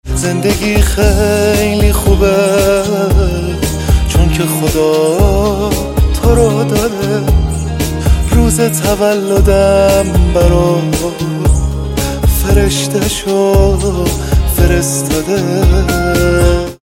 رینگتون نرم و باکلام